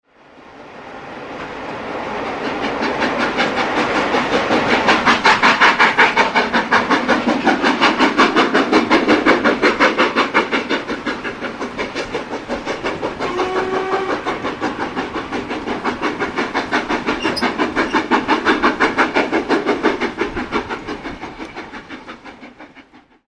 This fourth volume of tracks are all on-train recordings.